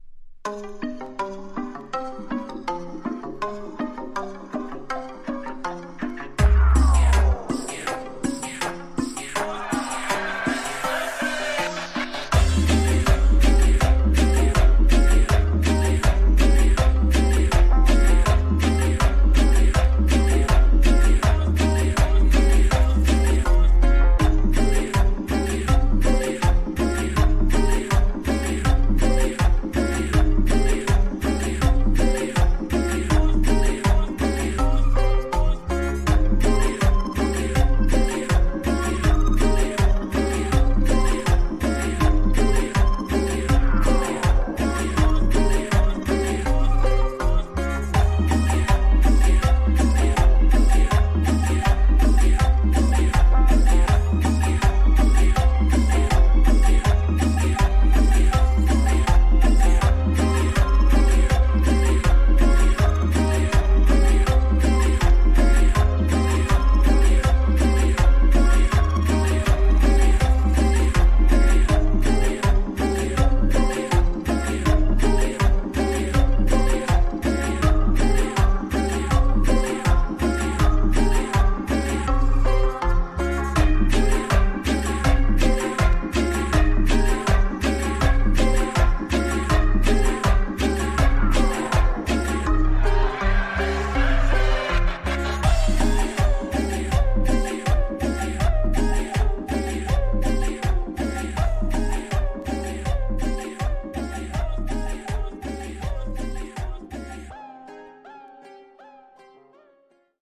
Tags: Cumbia , Sonidero